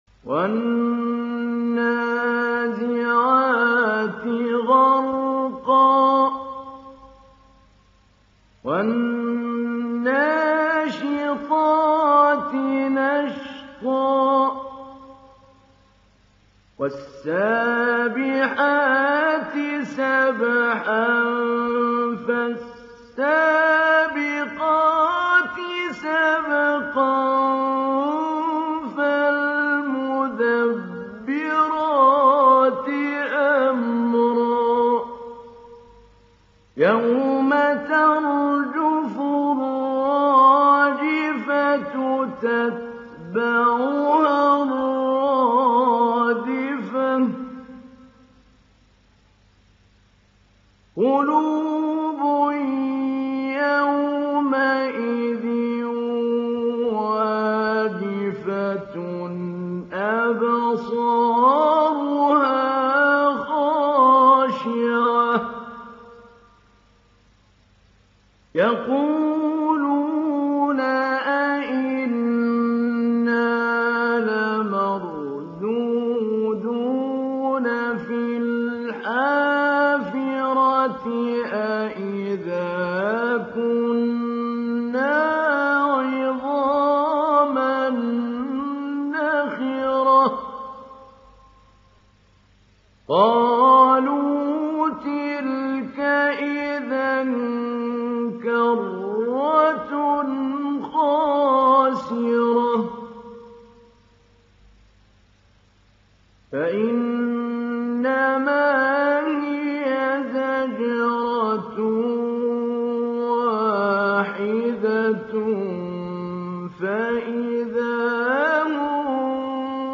Surah An Naziat mp3 Download Mahmoud Ali Albanna Mujawwad (Riwayat Hafs)
Download Surah An Naziat Mahmoud Ali Albanna Mujawwad